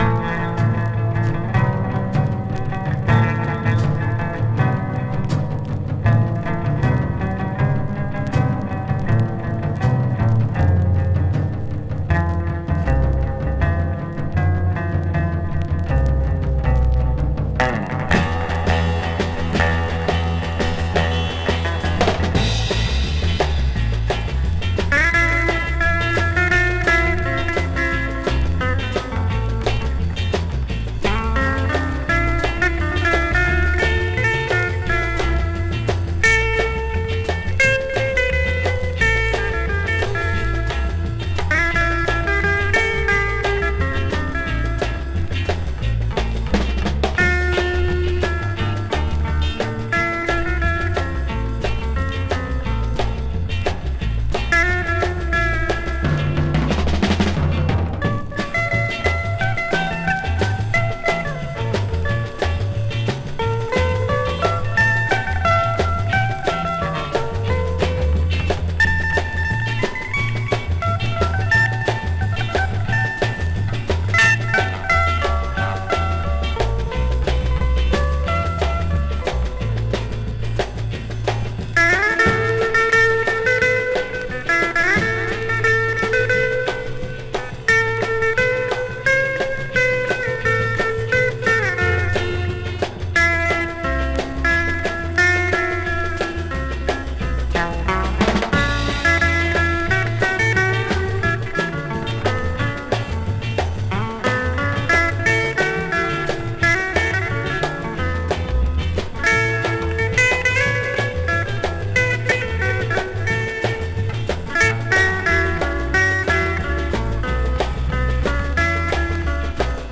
По ощущению - больше симбиоз сёрфа и твиста.